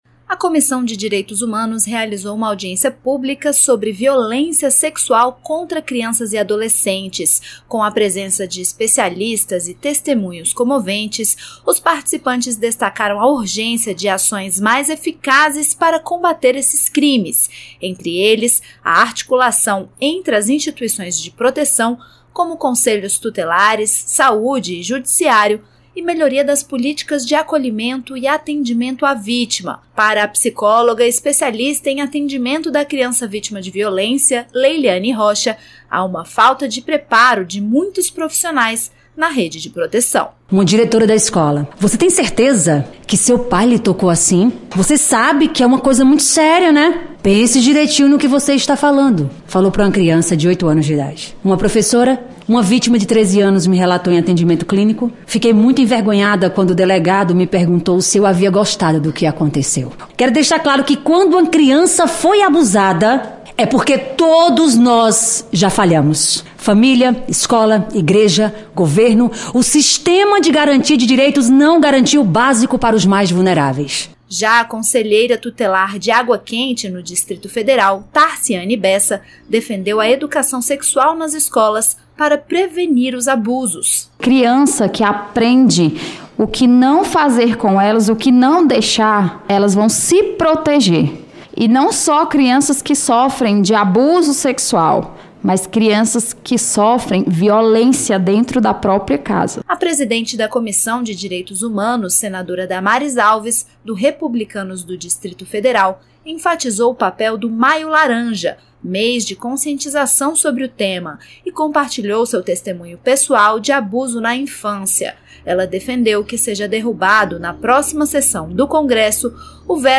Durante audiência na comissão de Direitos Humanos e Legislação Participativa nesta segunda-feira (19), foram discutidas políticas de proteção às crianças e adolescentes vítimas de violência sexual, com foco em prevenção, enfrentamento e acolhimento. A presidente do colegiado, senadora Damares Alves (Republicanos-DF), defendeu a derrubada pelo Congresso Nacional do veto feito ao Cadastro Nacional de Pedófilos e Predadores Sexuais.